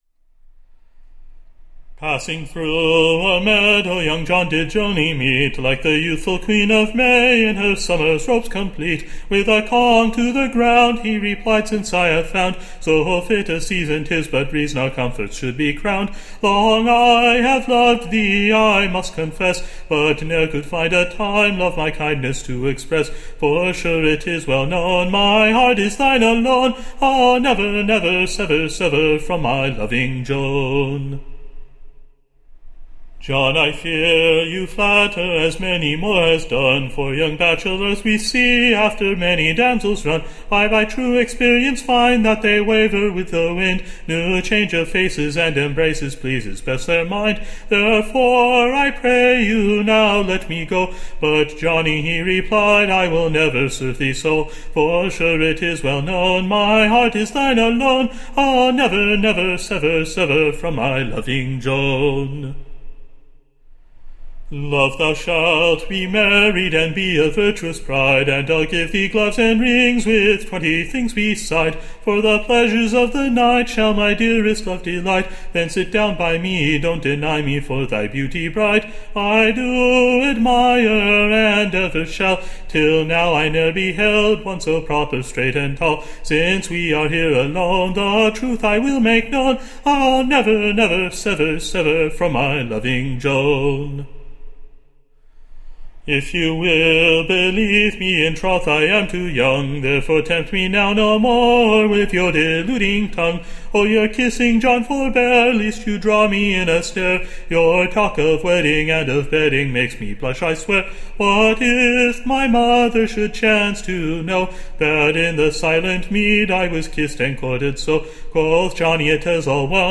Recording Information Ballad Title The Dorset=shire Lovers: / OR, THE / Honest innocent Wooing between John the Farmer and / his Sweet-heart Joan, as they happen'd to meet one Morning / in the midst of a green Meadow.